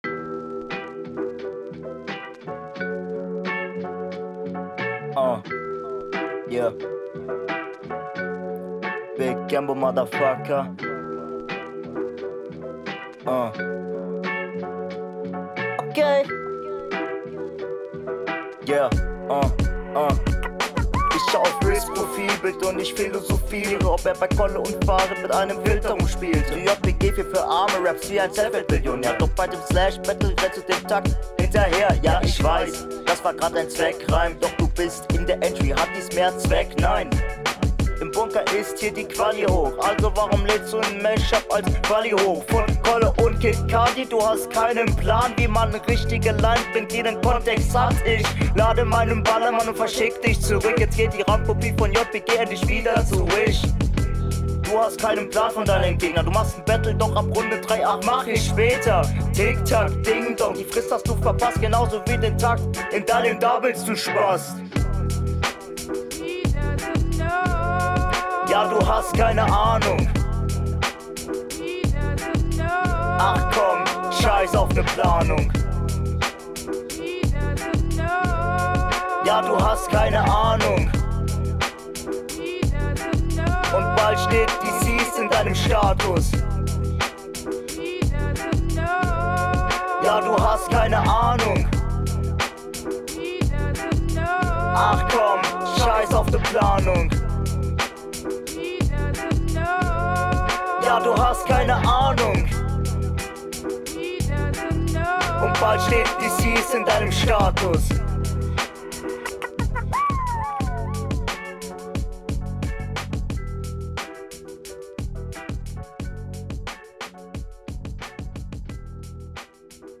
Joa der Beat passt schon eher zu, bist auch direkt verständlicher aber Stimme könnte immer …
Flow: Dein Flow kommt nicht an den deiner RR 1 ran ist aber trotzdem solide.